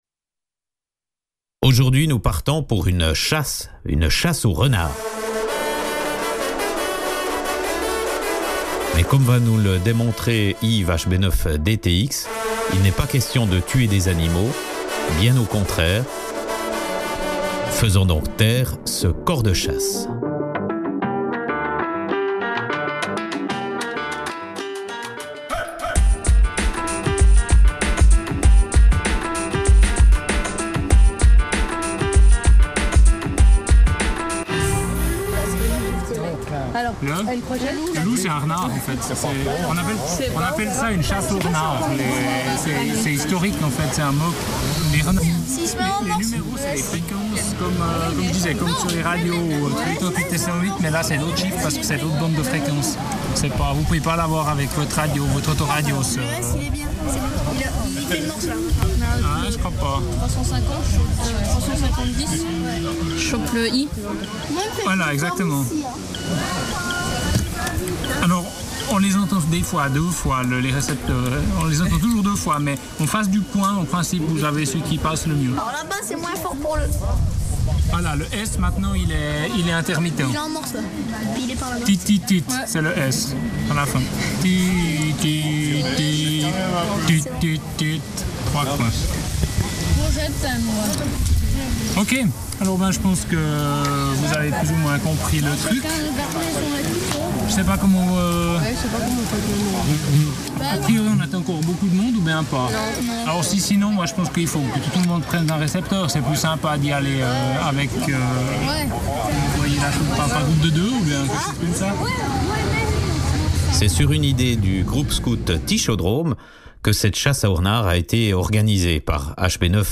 qui a eu lieu au Locle sous un soleil radieux dans la forêt autour de la piscine du Communal. Une bonne vingtaine d’éclaireurs se sont donnés à cœur joie à la recherche des 4 balises sur la bandes des 80m.
Voici mon reportage audio